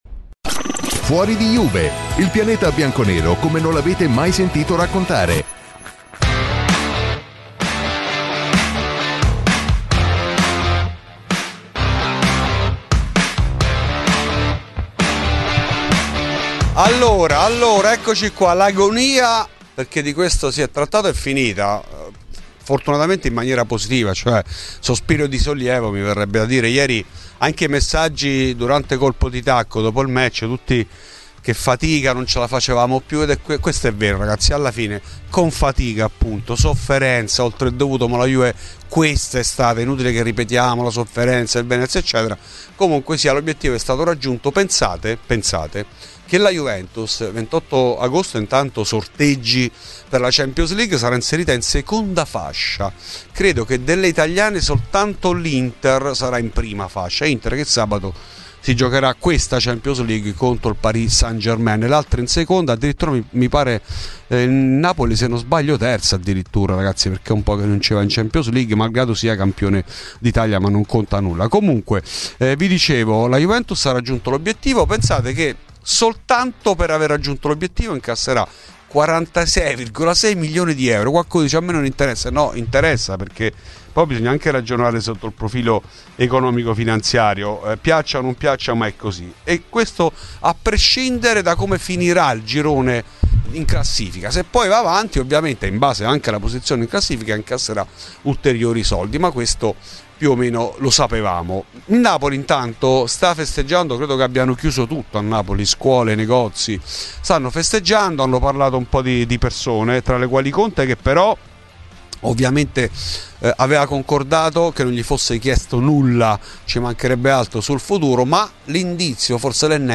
è intervenuto nella serata odierna ai microfoni di Radio Bianconera